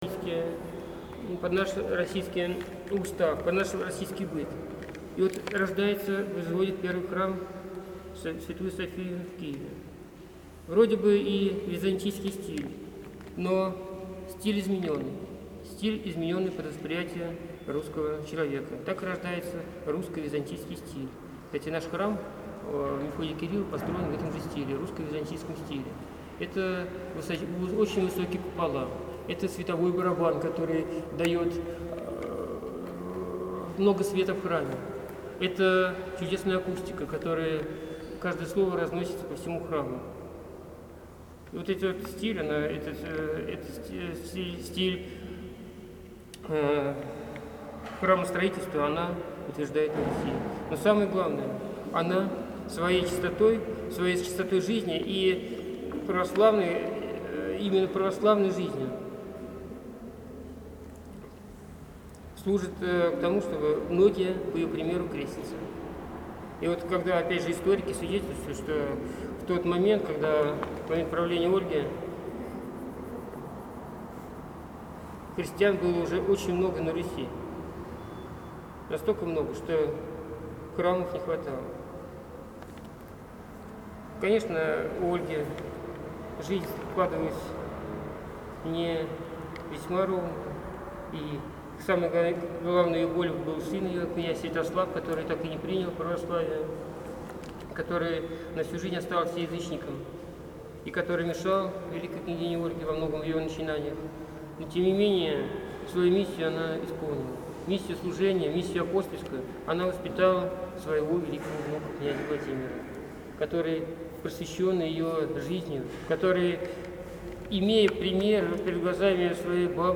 24 июля в храме святых равноапостольных Мефодия и Кирилла, учителей Словенских, состоялось заседание круглого стола, посвященного празднованию 1050-летия со дня преставления святой равноапостольной княгини Ольги.